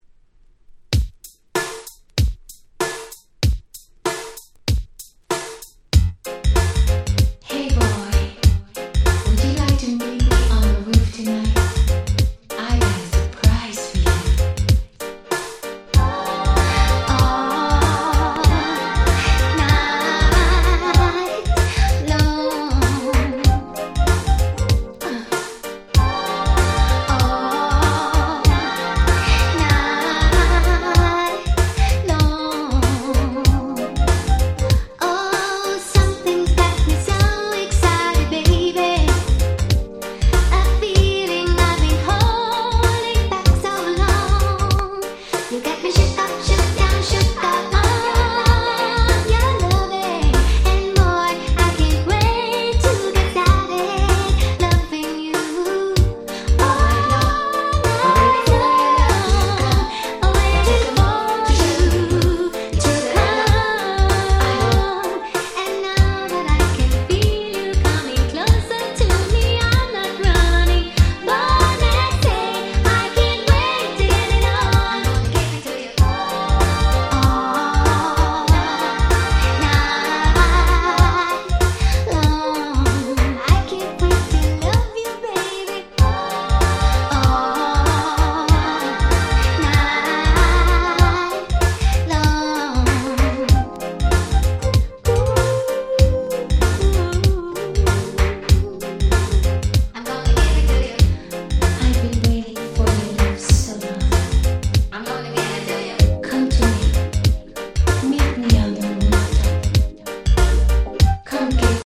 全て有名曲のLovers Reggaeカバー！